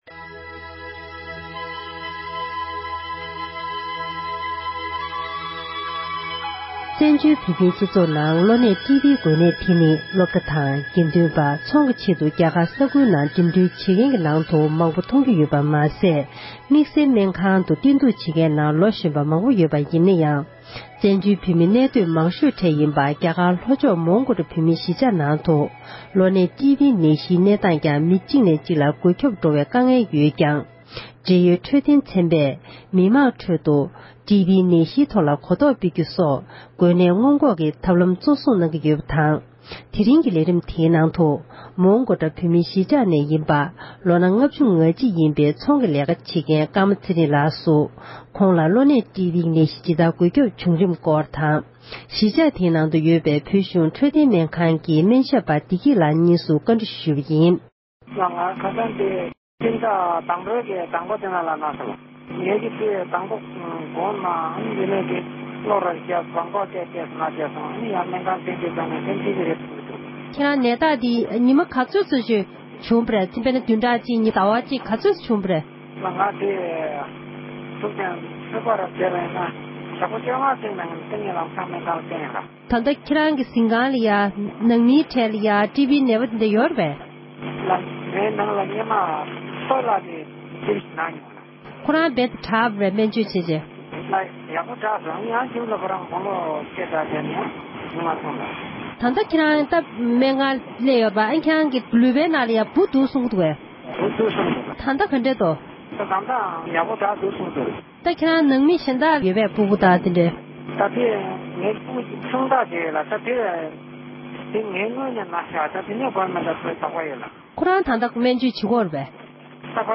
སྨན་ཞབས་པ་ཞིག་ལ་བཀའ་འདྲི་ཞུས་པ་ཞིག་གསན་རོགས་ཞུ༎